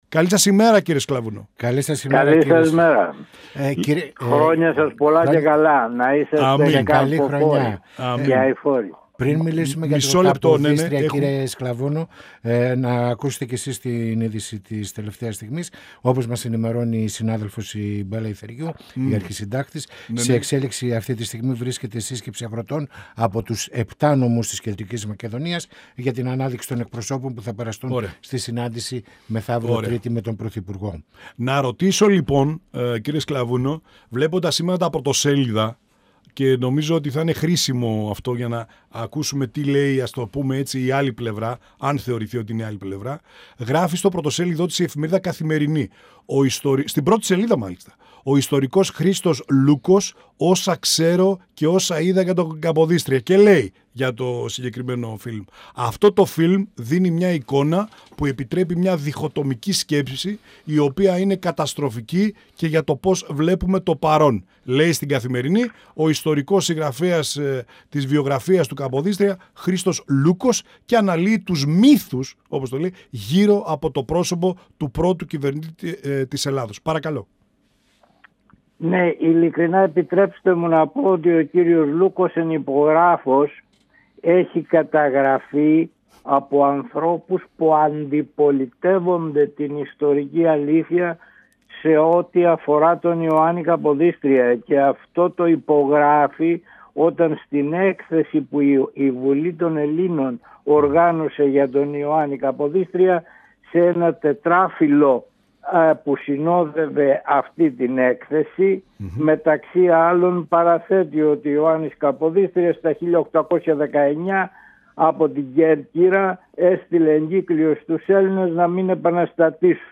Πανοραμα Επικαιροτητας Συνεντεύξεις